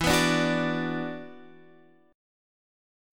E7sus2 chord